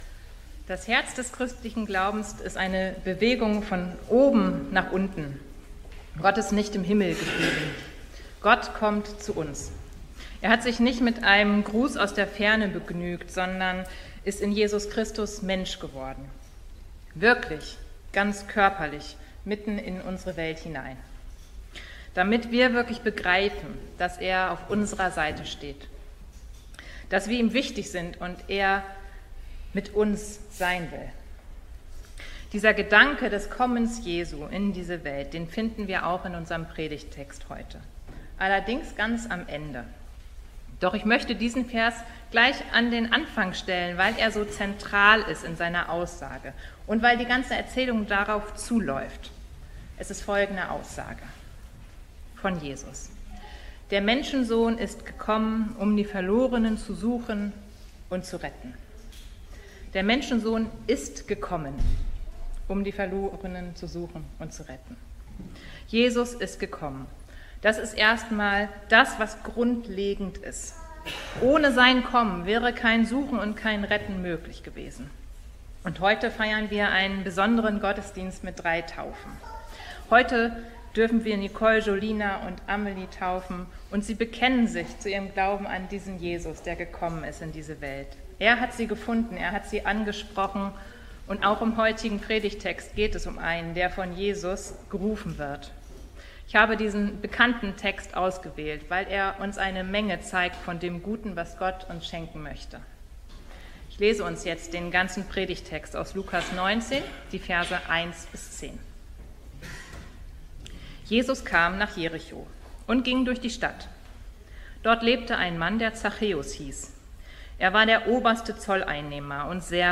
Predigten allgemein